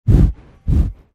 Звуки буйвола
Шум дыхания буйвола в микрофон